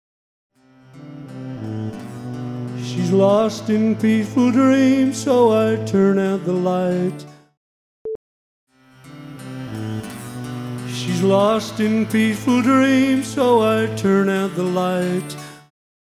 Here is a before-after of the treble boost I was on about